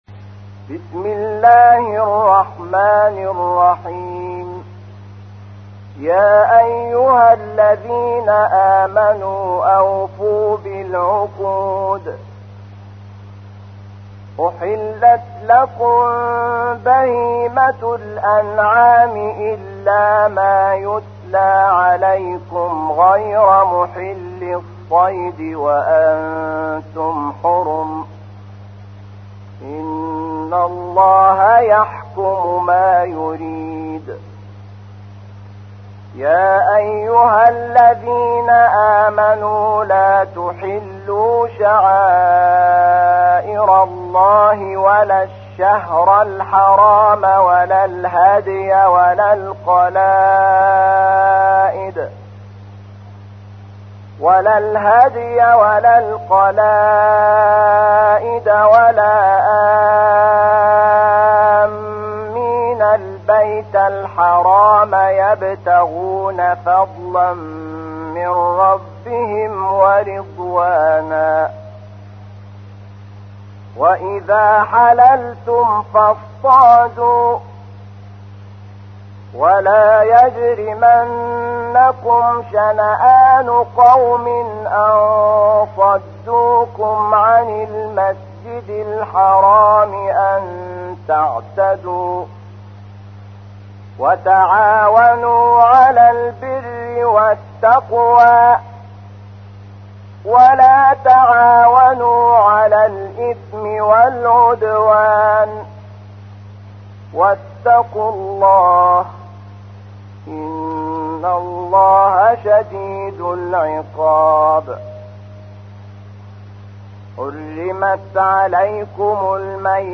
تحميل : 5. سورة المائدة / القارئ شحات محمد انور / القرآن الكريم / موقع يا حسين